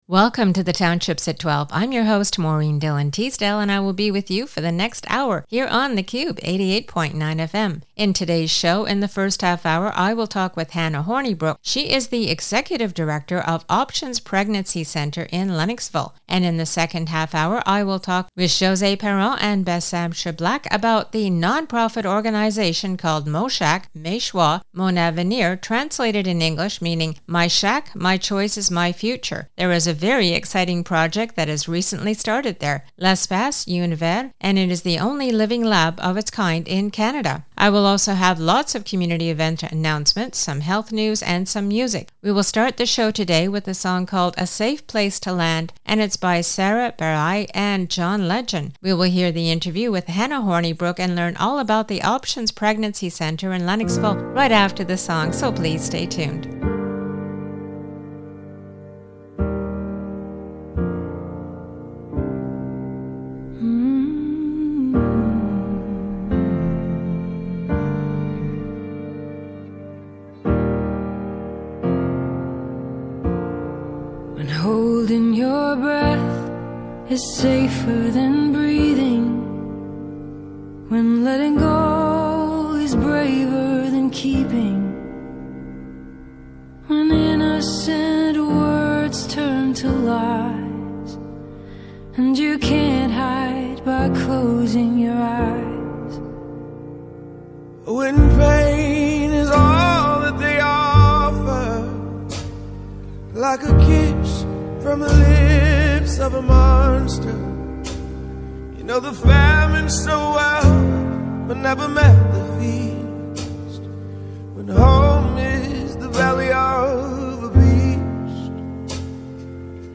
Interview de notre directeur sur CJMQ 88.9